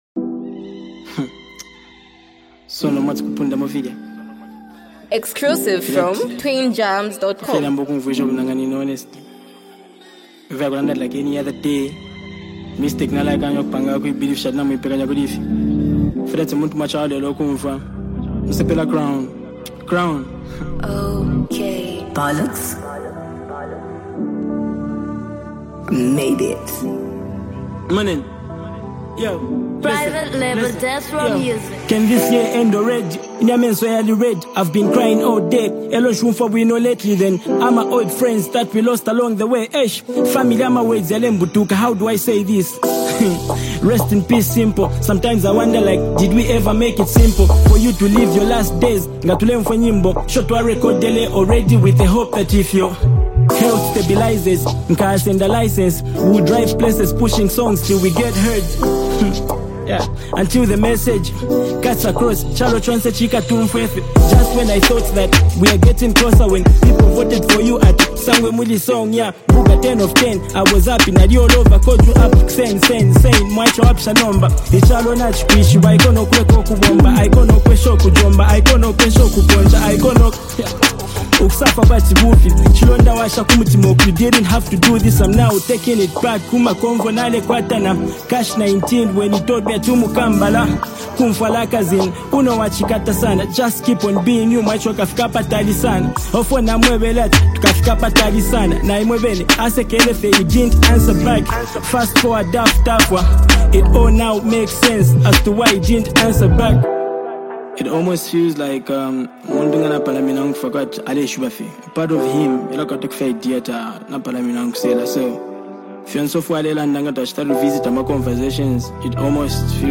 a deeply emotional and introspective track